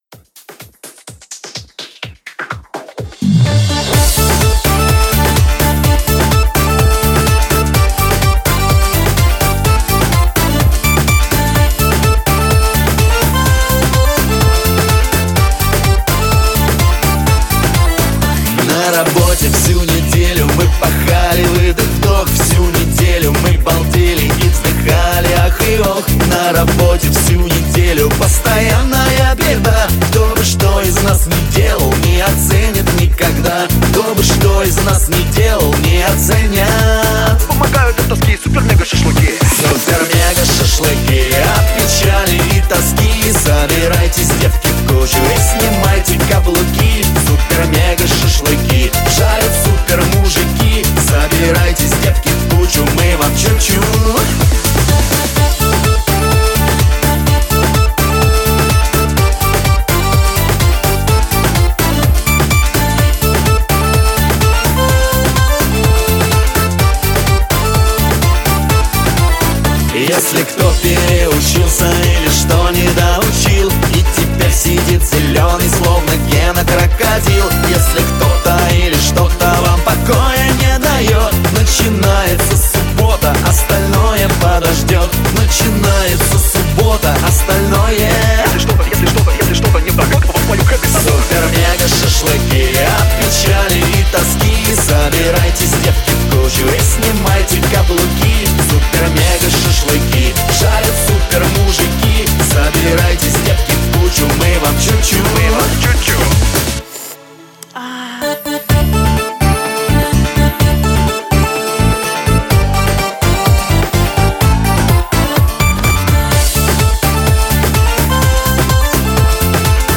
Категория: Застольные песни